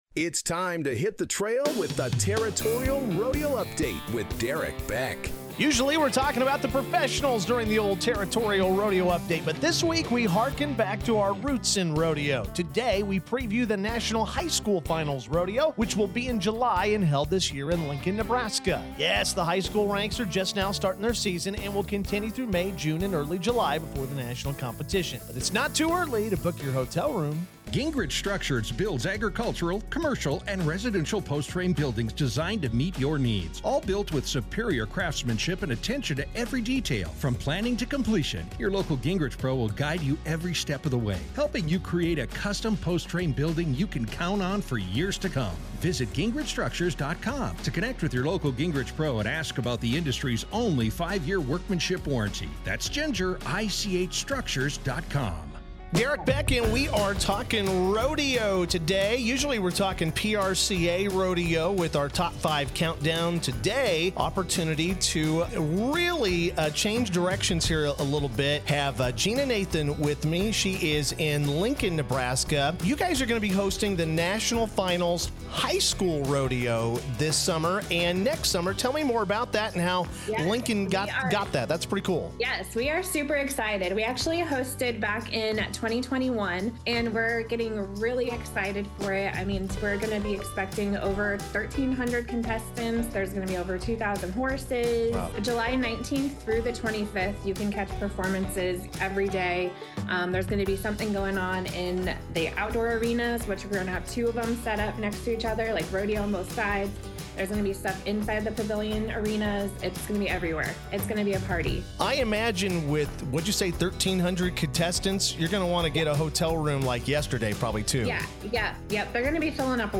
In an interview on the Territorial Rodeo Update